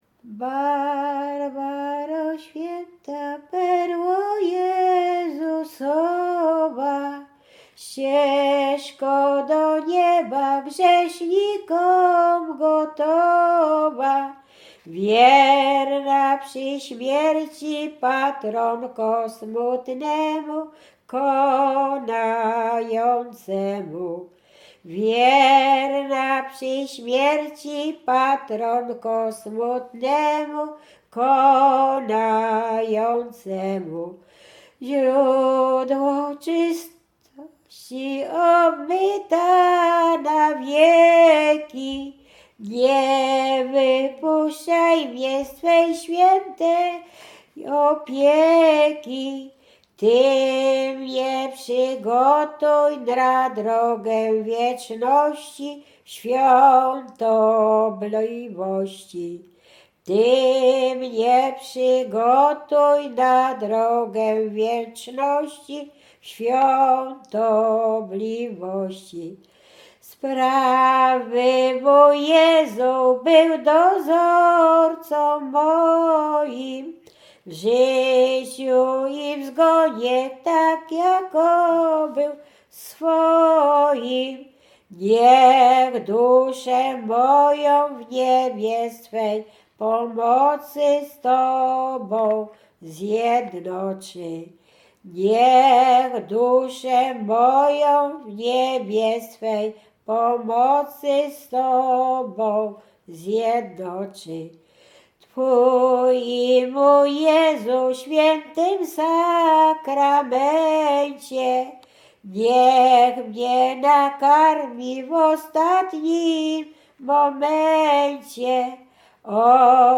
Łęczyckie
województwo łódzkie, powiat łódzki, gmina Zgierz, wieś Jasionka
Pogrzebowa
pogrzebowe nabożne katolickie do grobu o świętych